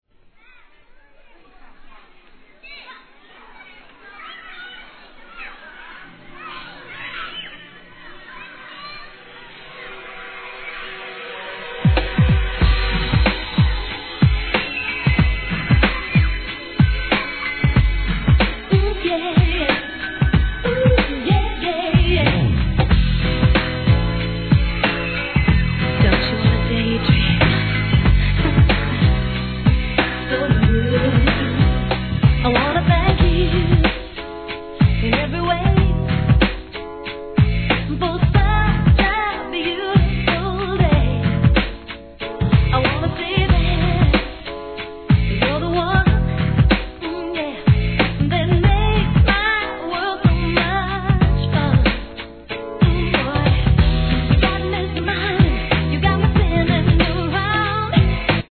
HIP HOP/R&B
女の子だけを第一義に作られたスーパー・ラブリー・テンダー6曲いり!!